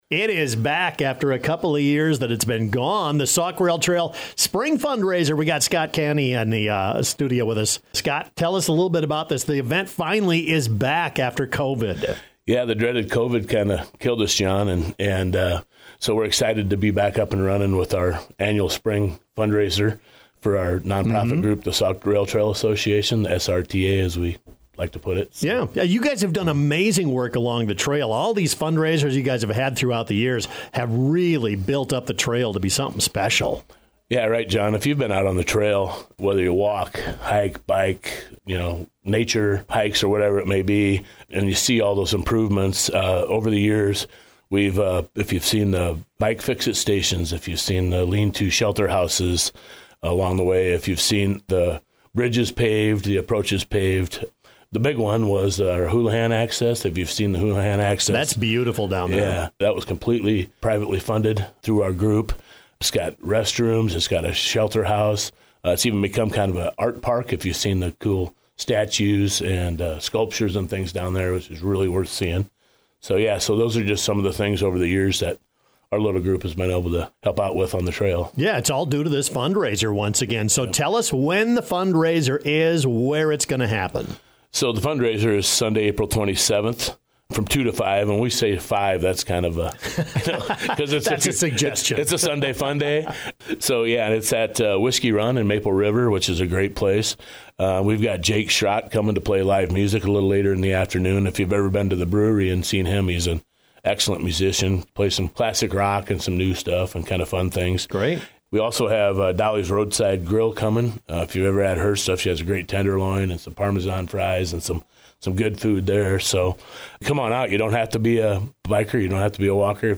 full interview about SRTA and the fundraiser event.